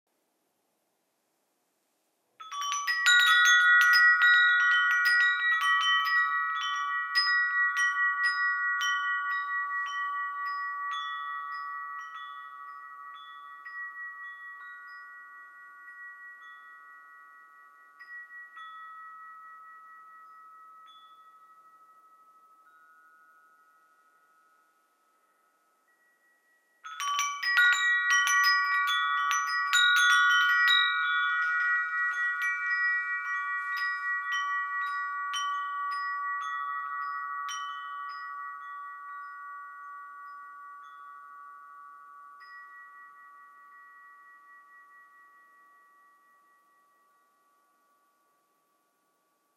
Dieses Chime zeichnet sich durch eine besonders gute Resonanz und einen langen Nachhall aus. Es ist auf den Grundton A4/a' 432 Hz gestimmt. Genießen Sie den hellen, aktivierenden Klang.